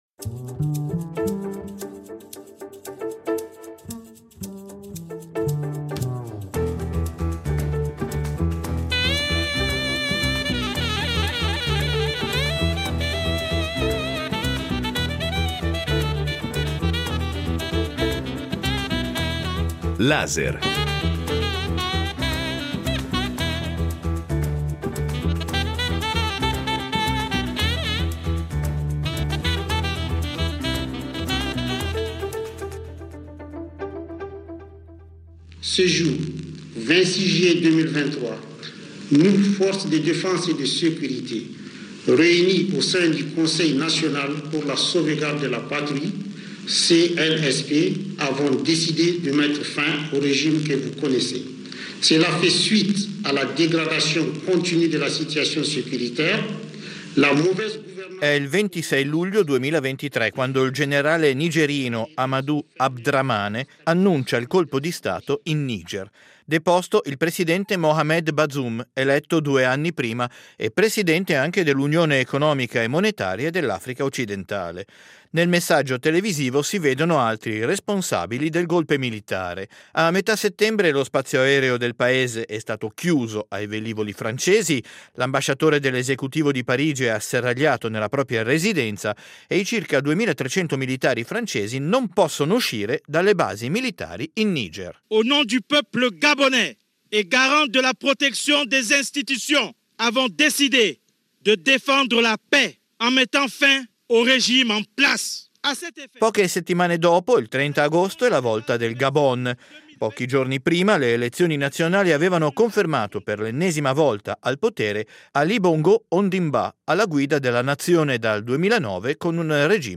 Incontro con l’antropologo